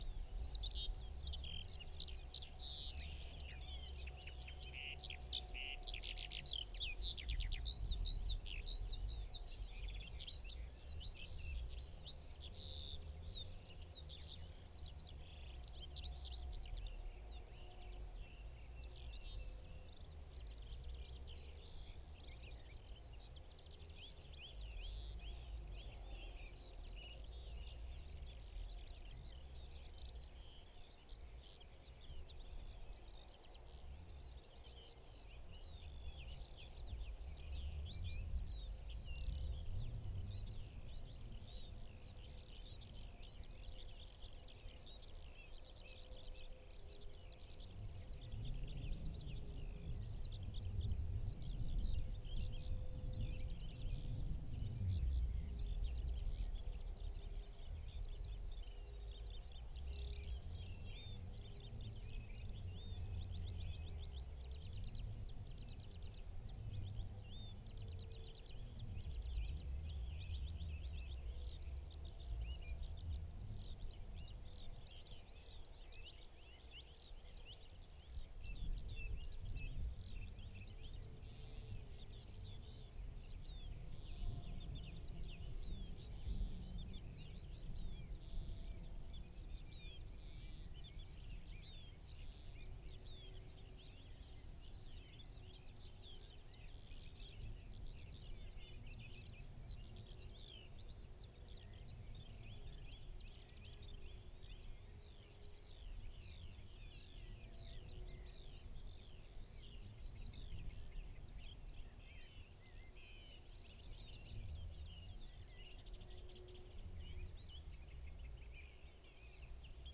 Alauda arvensis
Sylvia curruca
Phylloscopus collybita
Sylvia communis
Turdus merula
Sylvia atricapilla
Emberiza citrinella
Turdus philomelos